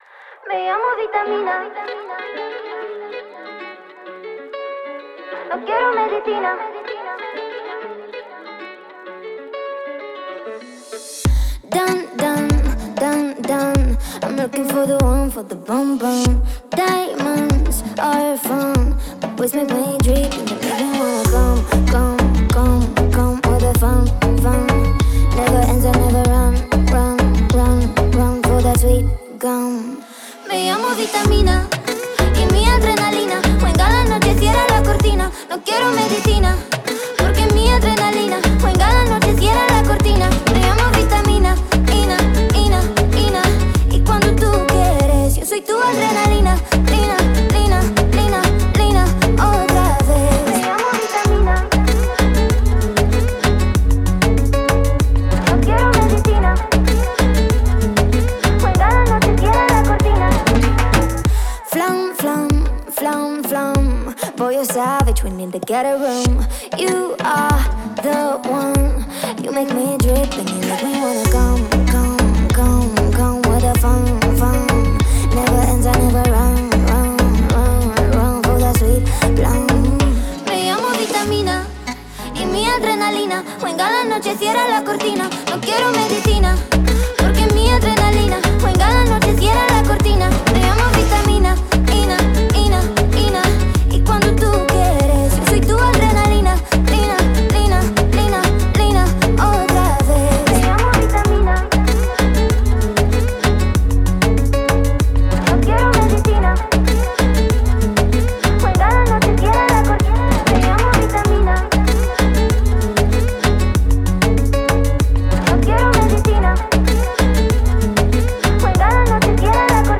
это зажигательная трек в жанре EDM